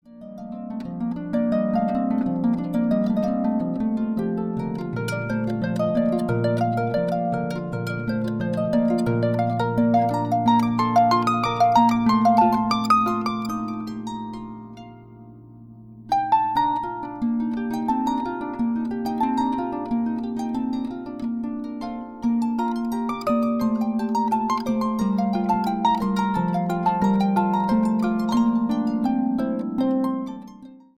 Harp Concerto
Classical